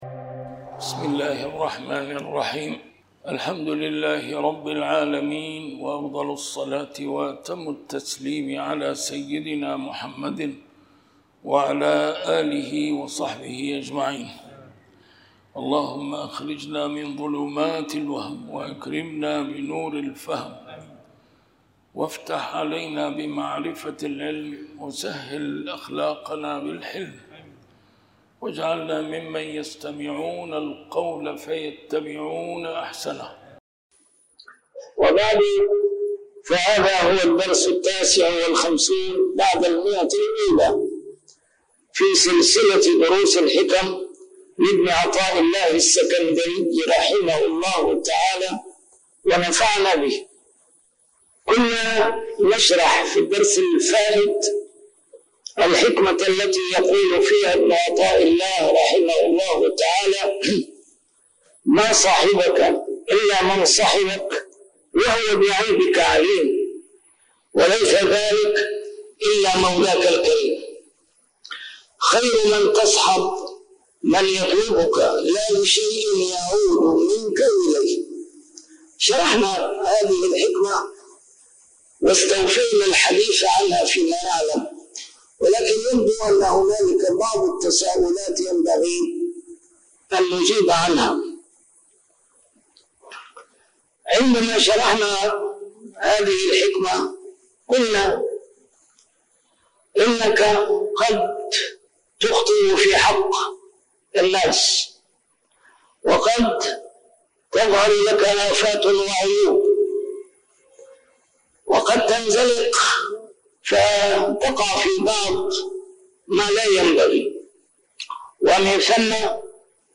الدرس رقم 159 شرح الحكمة 135+136